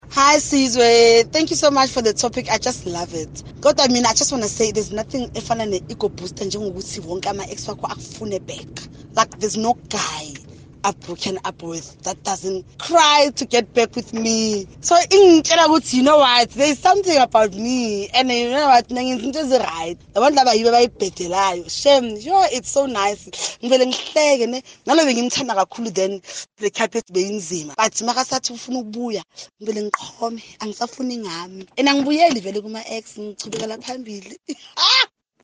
Here’s how Kaya Drive listeners felt about comparisons with their ex’s new partner: